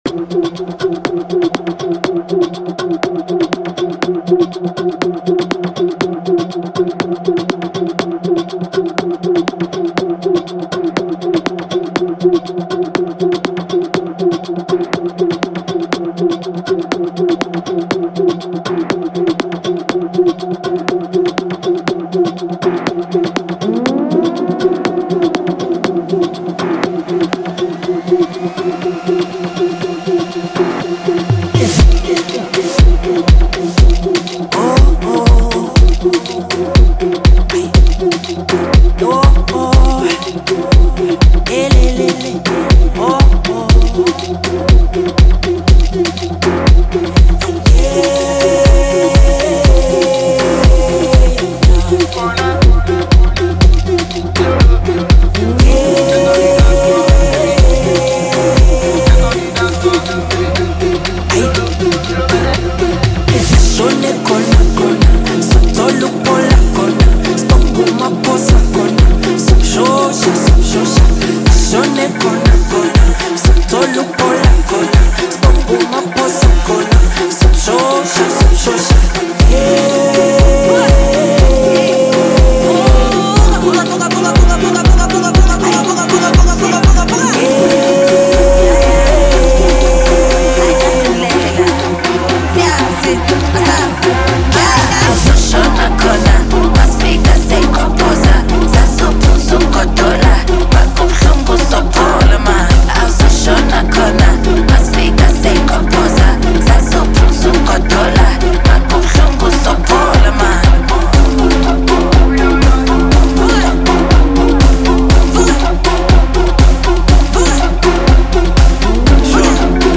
Home » Amapiano » Music